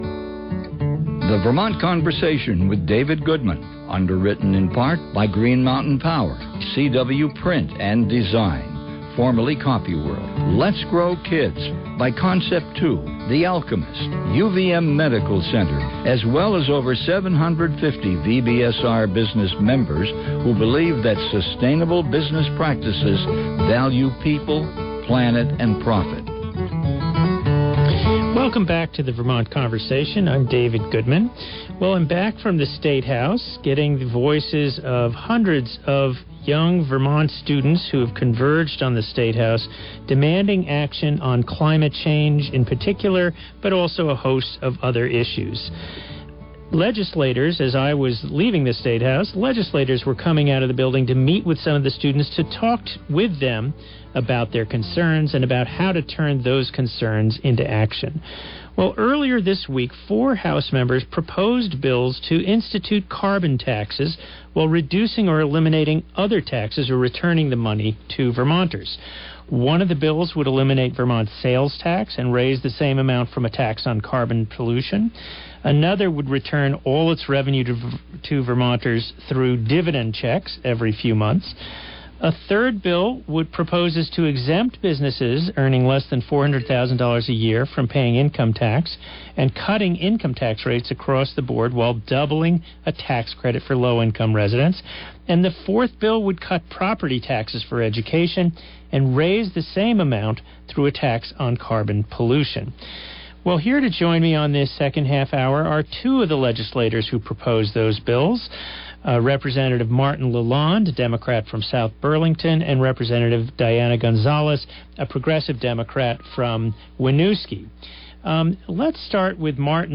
We talk with two of the bills’ sponsors about how Vermont could put a price on carbon. (April 12, 2017 broadcast)